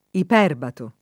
iperbato [ ip $ rbato ]